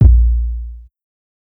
KICK_DONT_DO_SHIT.wav